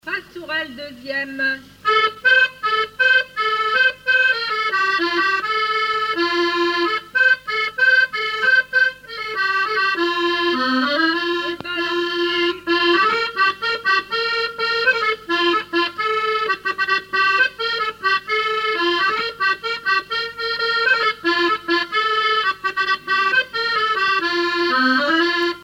Quadrille - Pastourelle deuxième
danse : quadrille : pastourelle
Musique du quadrille local
Pièce musicale inédite